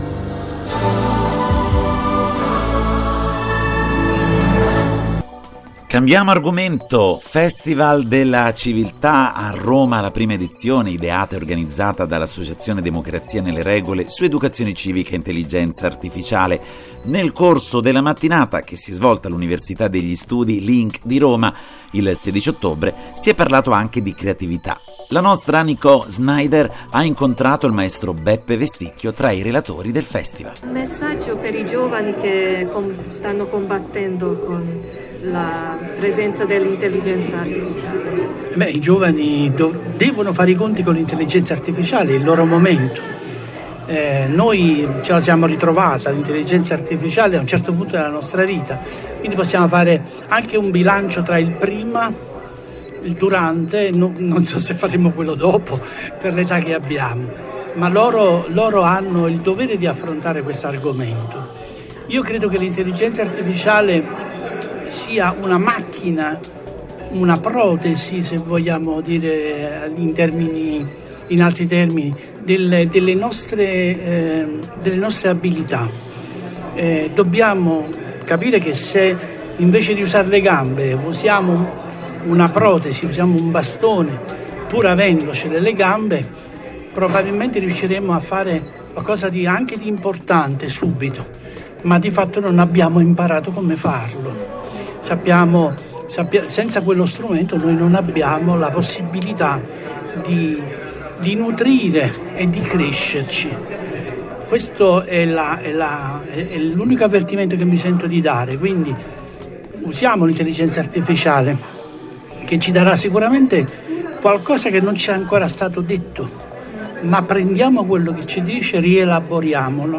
Nel corso della mattinata che si è svolta all’Università degli Studi Link di Roma il 16 ottobre, si è parlato anche di creatività.